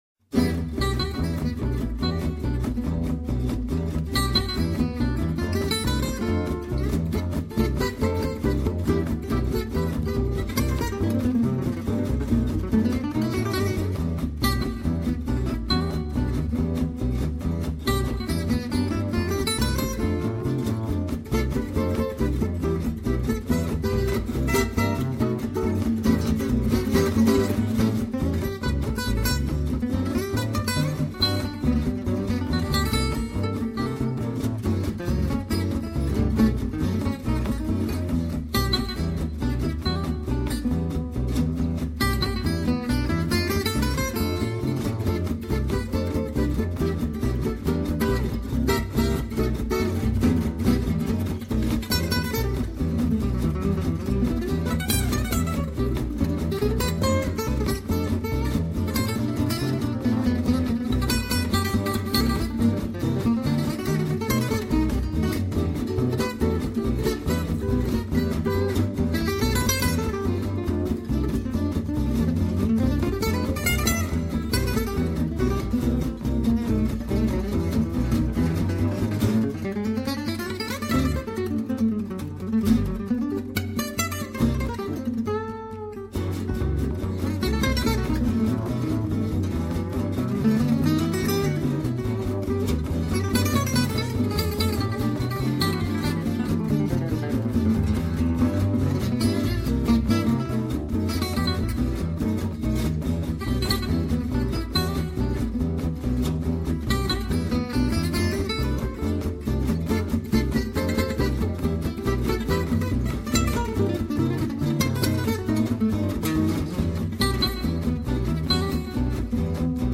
seconde guitare
basse, batterie et percussions.
guitare manouche